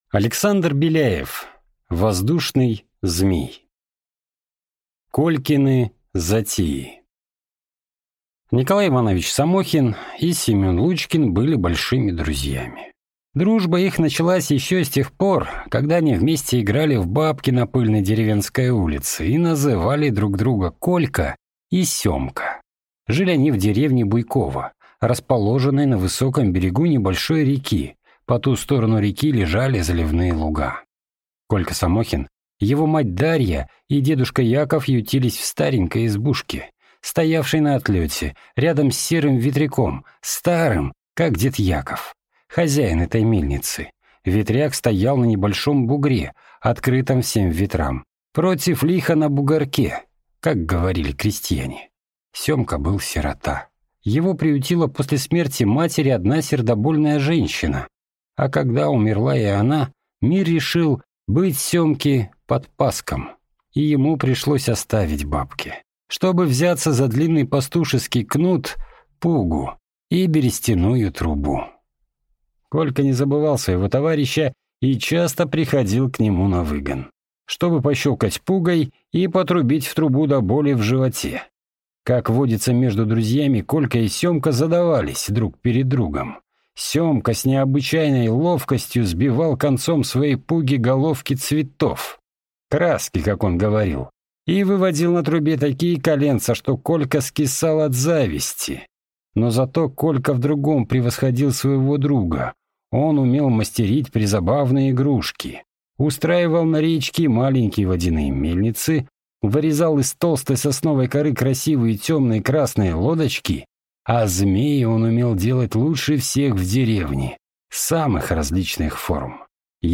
Аудиокнига Воздушный змей | Библиотека аудиокниг